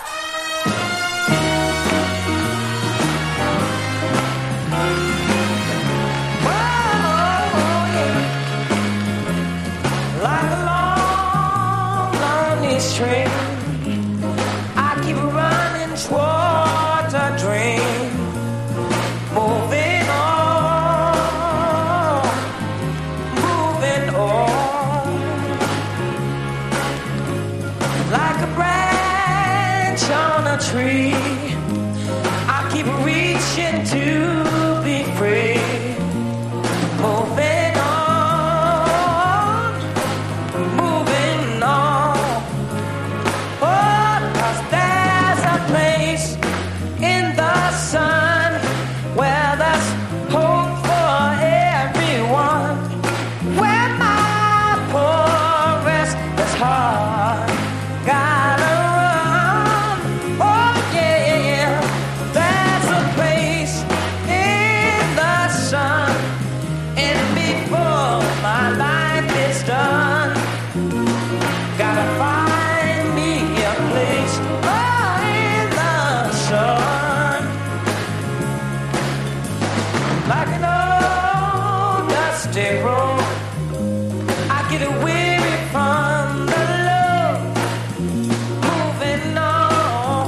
1968年2月13日東京渋谷公会堂での実況録音！